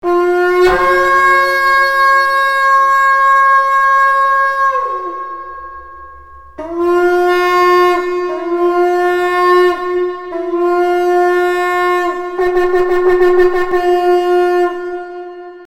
Het geluid van de Shofar....
shofar 3 tunes.mp3